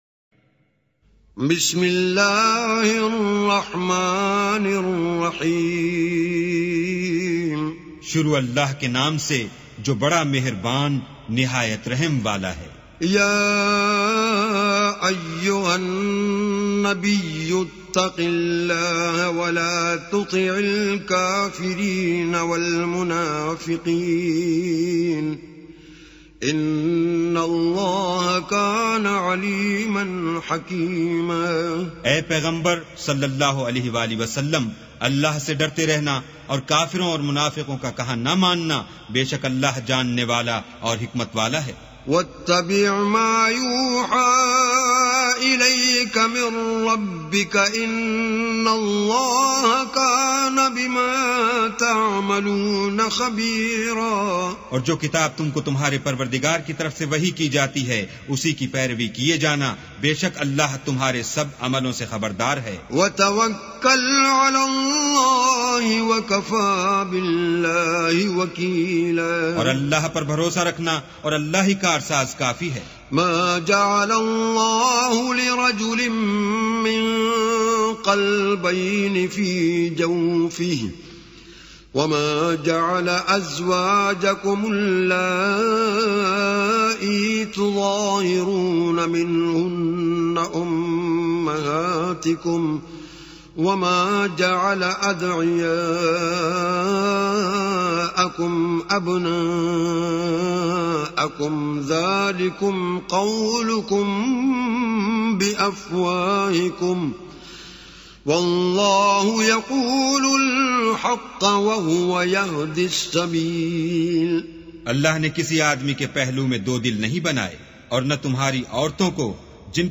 arabic recitation